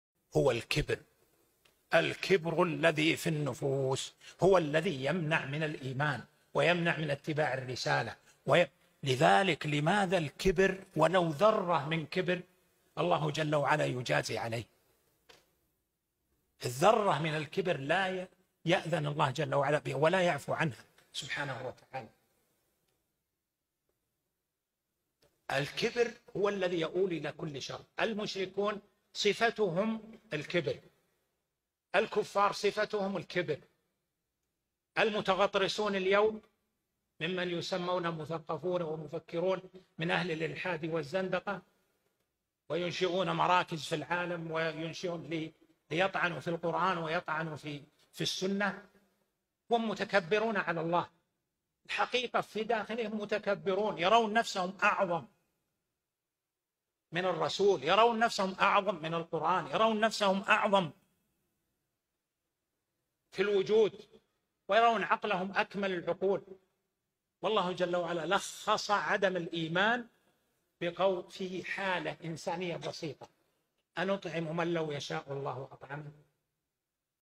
Ο λόγιος Σέηχ Σάλιχ Ίμπν ΑμπντουλΑζίζ αλ Σέηχ σχολιάζει το εδάφιο λέγοντας: